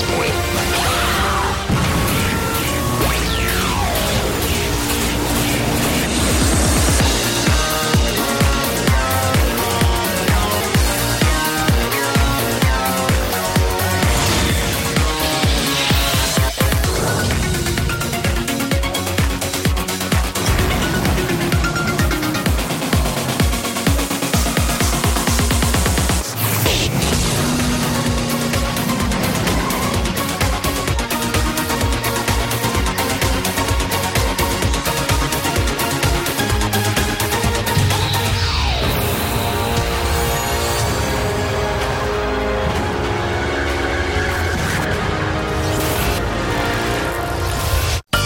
Sounds a little like Australian Big Brother circa 2007